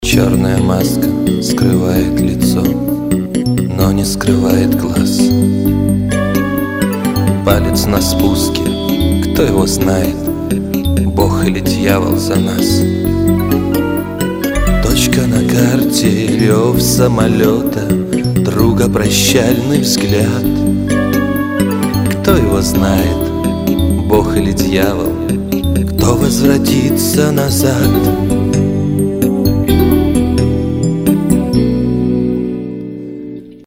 грустные
спокойные
лирические
военные
Военно-патриотическая песня